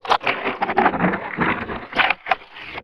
ALIEN_Communication_04_mono.wav